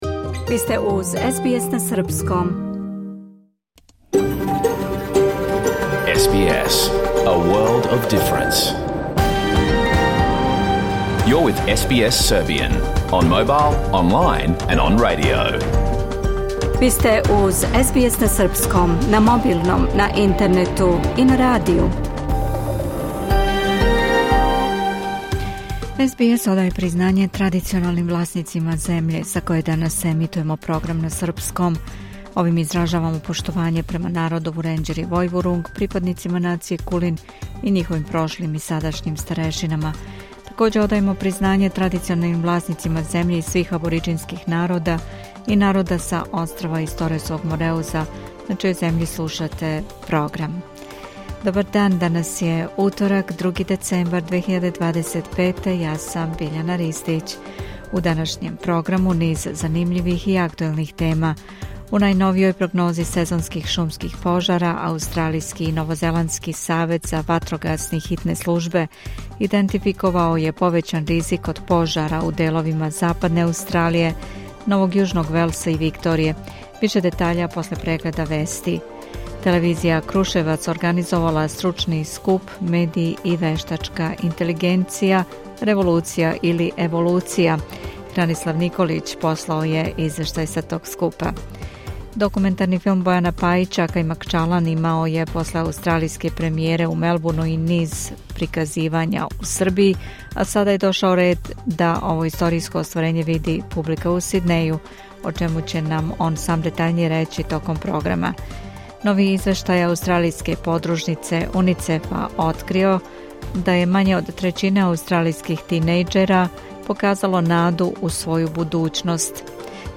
Програм емитован уживо 2. децембра 2025. године